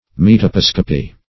Search Result for " metoposcopy" : The Collaborative International Dictionary of English v.0.48: Metoposcopy \Met`o*pos"co*py\, n. [Gr.